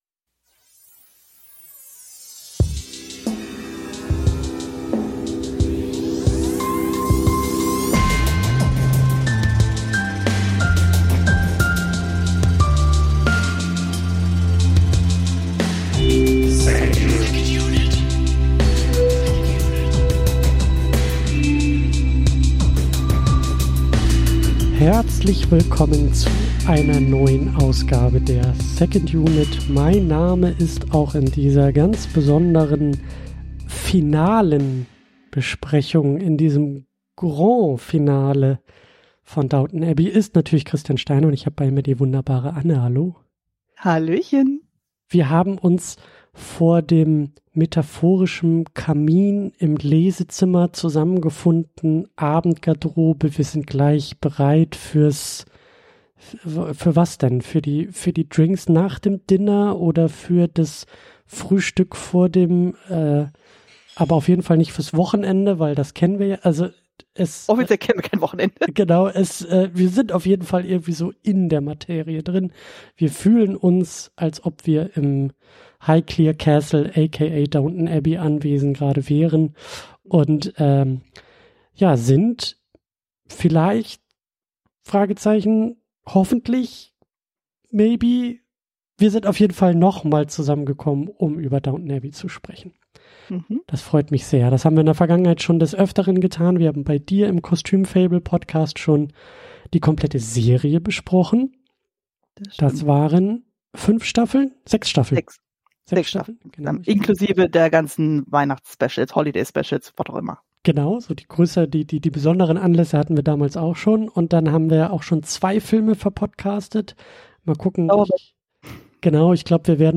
In einer Mischung aus philosophischem Gespräch und filmwissenschaftlichem Seminar entsteht dabei ein ganz eigenes Filmgespräch.